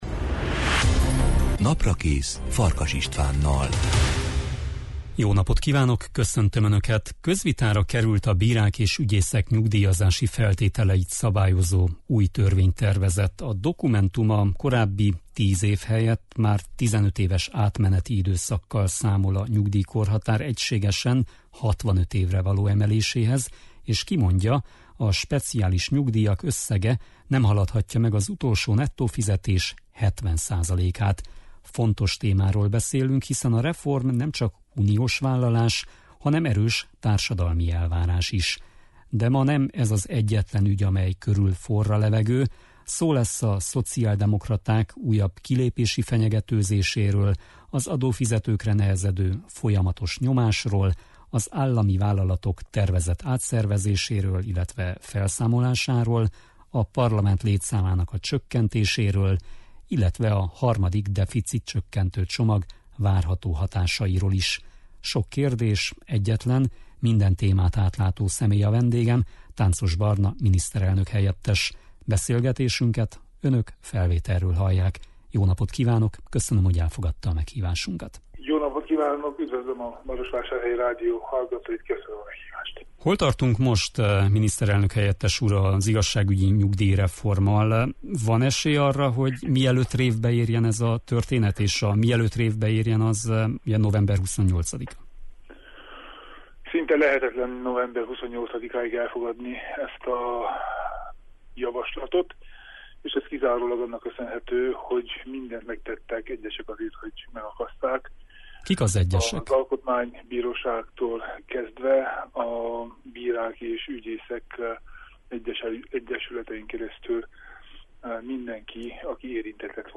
Tánczos Barna miniszterelnök-helyettes a Naprakész venedége.